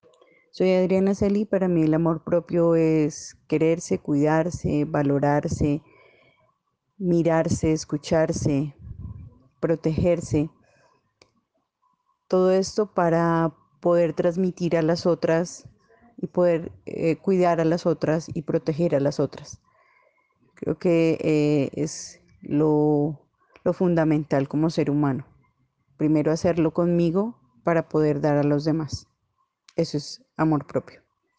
Narración oral de una mujer de la ciudad de Bogotá que define el amor propio desde el quererse, cuidarse, valorarse y escucharse para así cuidar y proteger de las otras. El testimonio fue recolectado en el marco del laboratorio de co-creación "Postales sonoras: mujeres escuchando mujeres" de la línea Cultura Digital e Innovación de la Red Distrital de Bibliotecas Públicas de Bogotá - BibloRed.